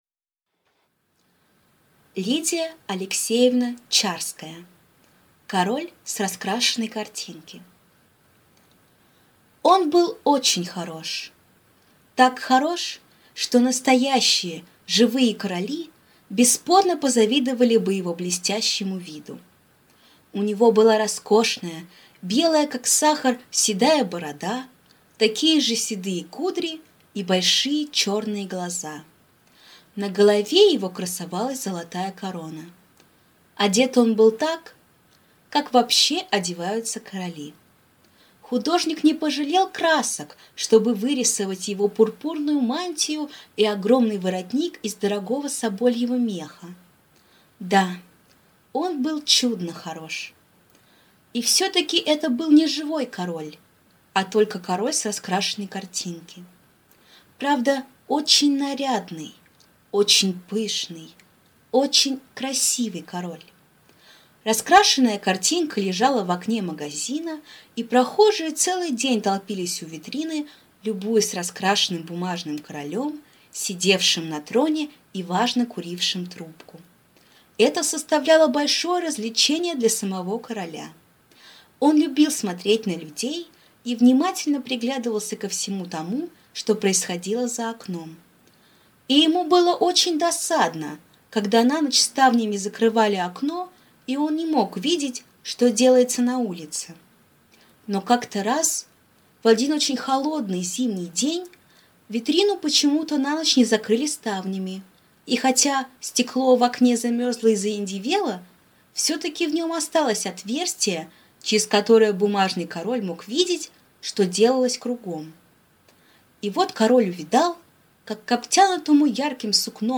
Аудиокнига Король с раскрашенной картинки | Библиотека аудиокниг